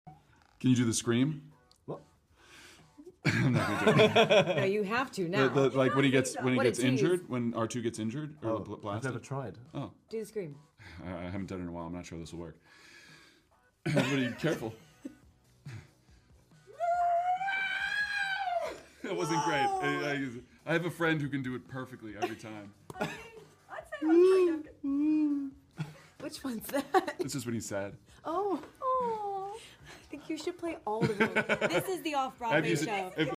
David Corenswet Doing R2d2's Iconic Sound Effects Free Download
david corenswet doing r2d2's iconic